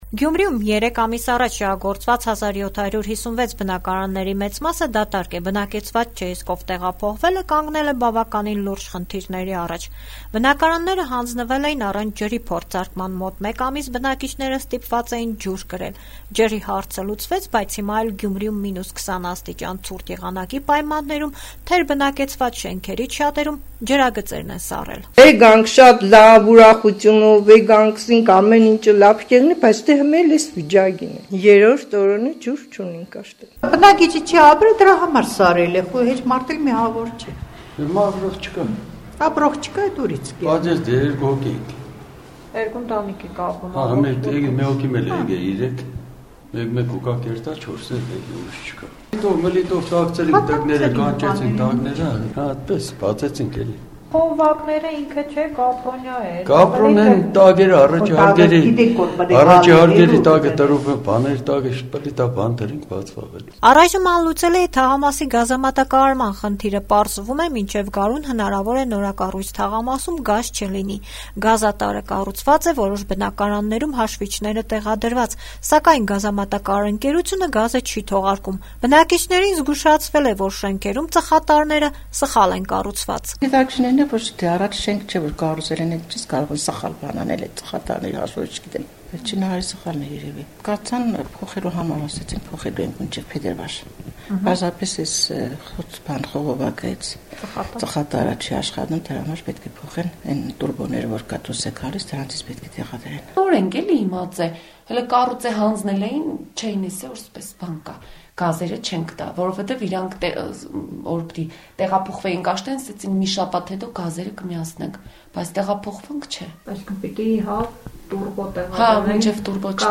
«Ազատություն» ռադիոկայանի հետ զրույցներում Գյումրիի նորակառույց թաղամասի բնակիչները բողոքում են անորակ ու թերի կատարված աշխատանքներից: